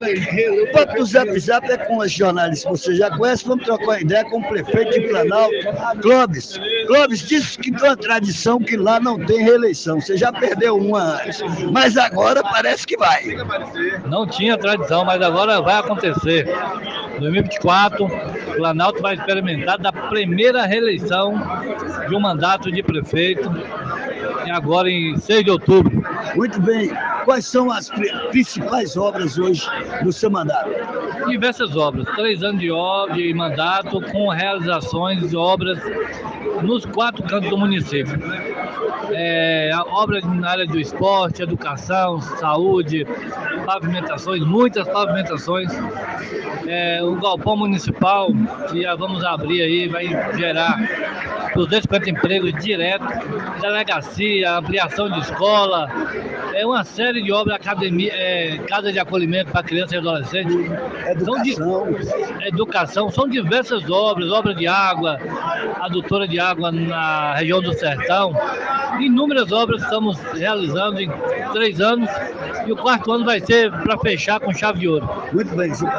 na última sexta-feira (26) no coquetel de lançamento da Exposição 2024 de Vitória da Conquista